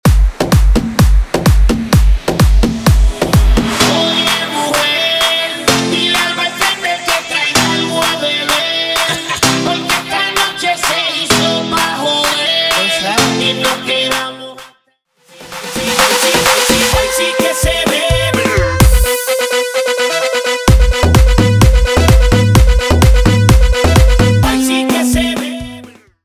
guaracha
cumbia remix